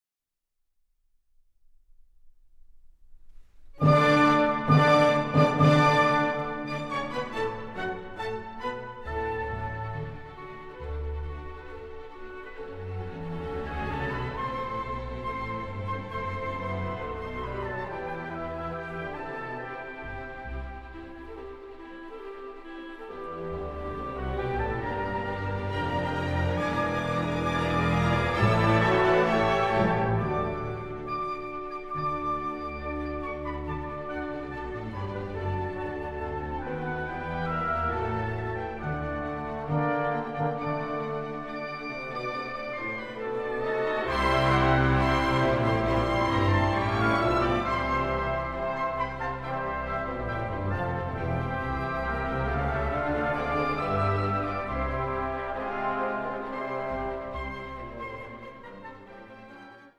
流派: 古典乐